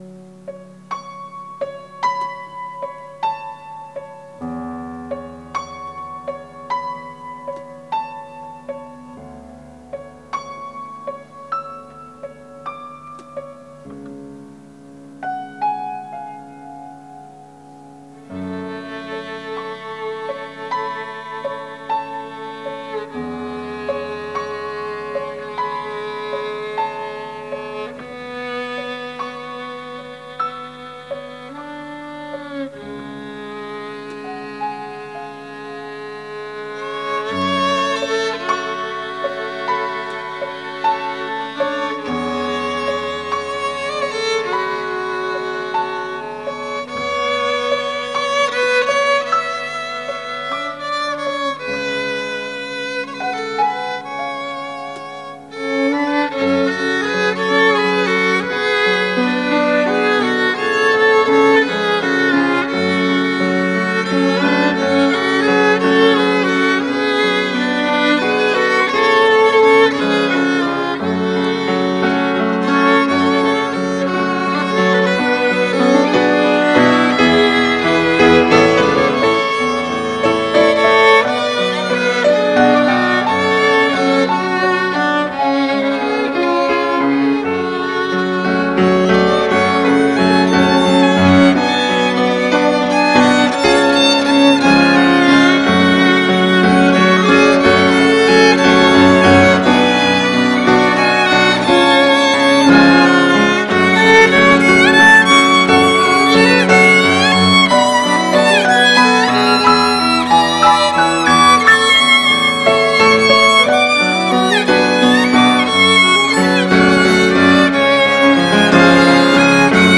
Конференция молодежи ОЦХВЕ Сибири 2019
Я жду Тебя, люблю Тебя - Молодежь (г. Иркутск) (Пение)[